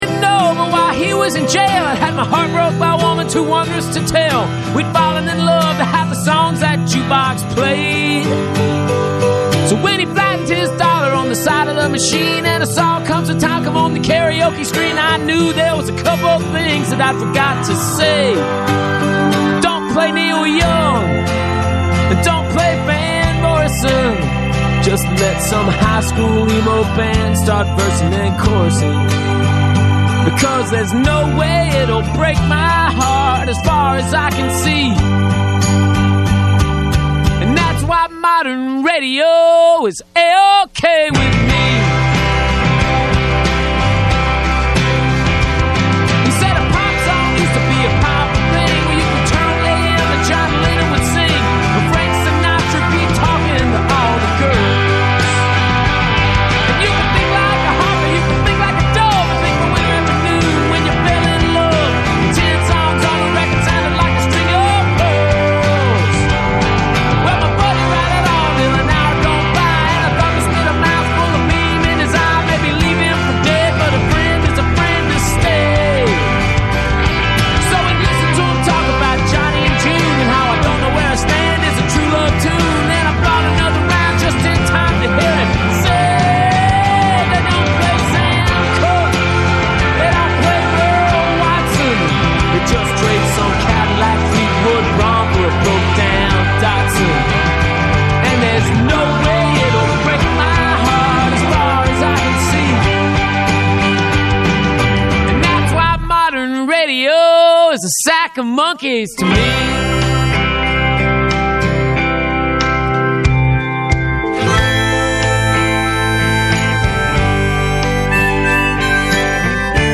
radio show with musical guest